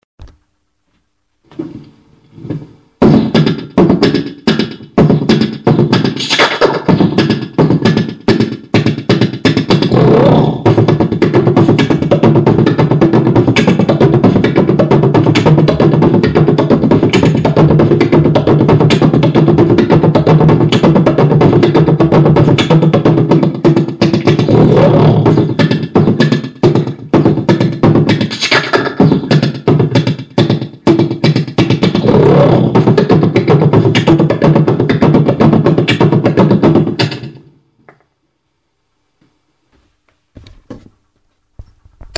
Вот мой не большой бит я занимаюсь битбоксом уже ниделю строго не судите ну скачайте по ржоте